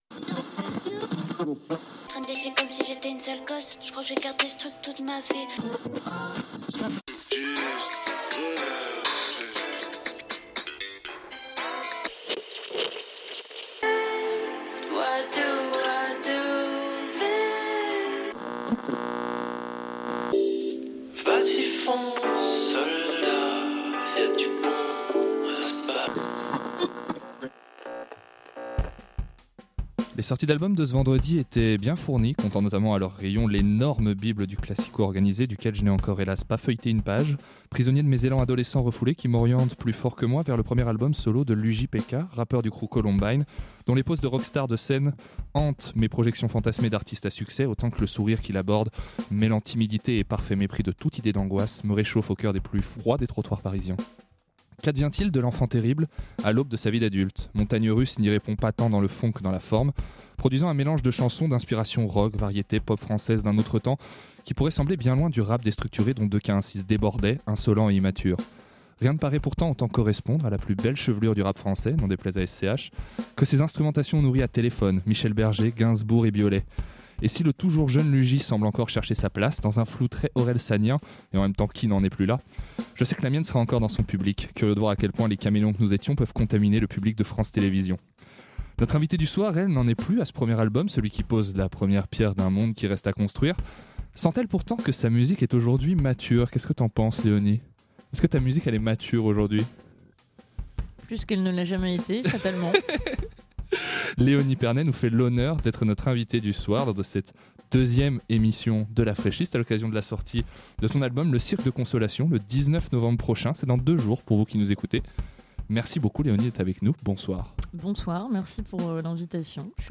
Cette saison, la Fraîche Liste met à l'honneur les artistes qu'elle diffuse dans une émission mensuelle faite de découvertes, de confessions et de musique. Les trois programmateurs.rices vous invitent à faire plus ample connaissance avec l'une des voix qui les a sédui.te.s ce mois-ci, et à découvrir la sélection mensuelle plus en profondeur.